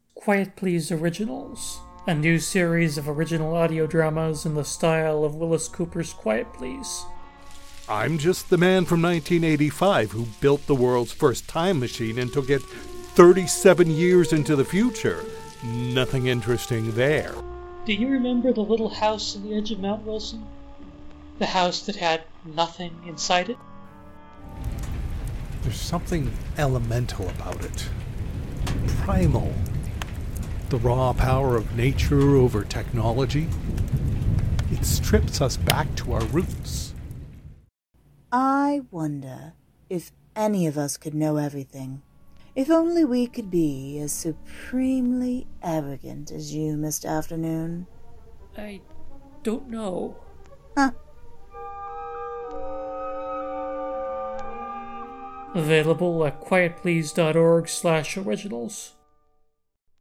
Format: Audio Drama
Voices: Full cast
Narrator: First Person
Genres: Multigenre, Old time radio
Trailer